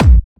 Kick b.wav